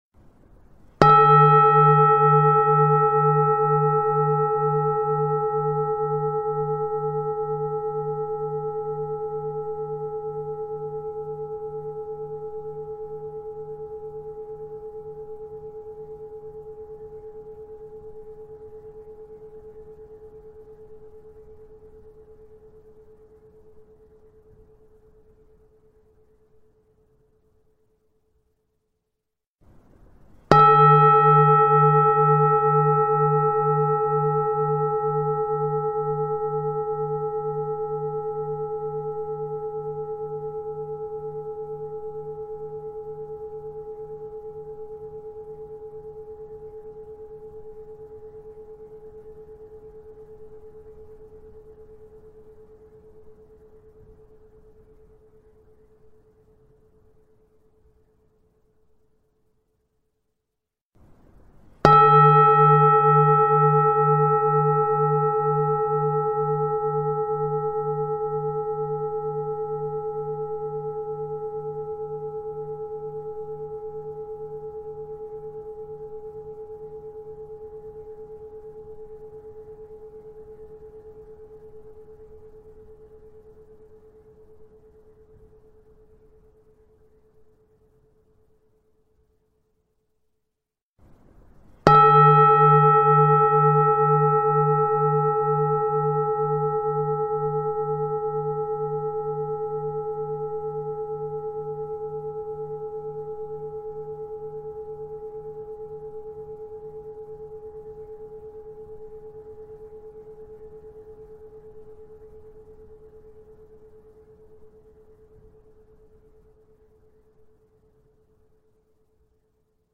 Tiếng Chuông Chùa ngân vang liên tục
Thể loại: Tiếng chuông, còi
Description: Âm vang ngân nga, réo rắt, khi trầm lắng, khi ngân dài, mang theo năng lượng tịnh hóa, giúp xua tan muộn phiền và đưa tâm hồn trở về trạng thái tĩnh lặng, an yên. Hiệu ứng âm thanh tiếng chuông chùa được sử dụng trong chỉnh sửa video mang lại cảm giác thư thái, thanh tịnh, gợi mở sự tĩnh tâm và an lạc nội tại, thích hợp cho thiền định, thư giãn và nội soi tâm hồn.
tieng-chuong-chua-ngan-vang-lien-tuc-www_tiengdong_com.mp3